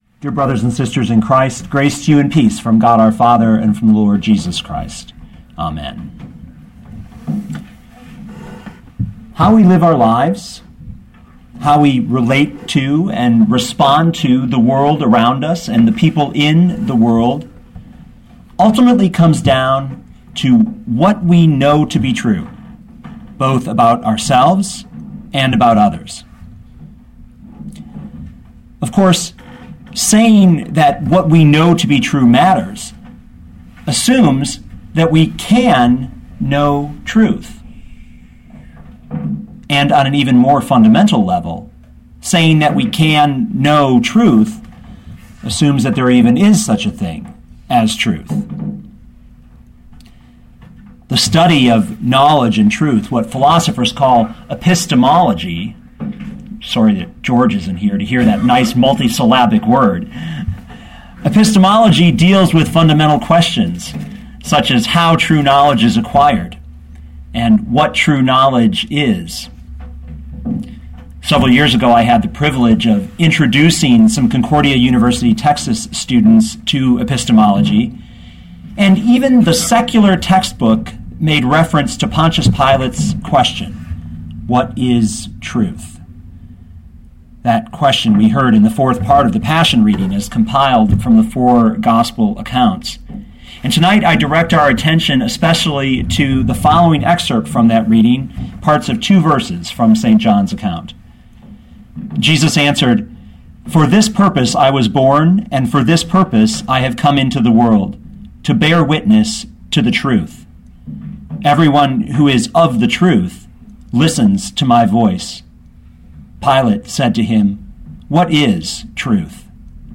2012 John 18:37-38 Listen to the sermon with the player below, or, download the audio.